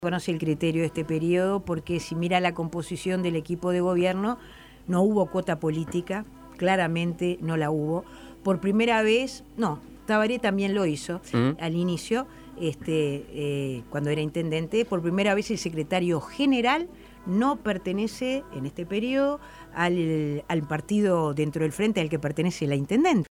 Entrevistada por Suena Tremendo, Olivera respondió a Martínez, que había dicho que él, en caso de ganar, va a poner a los mejores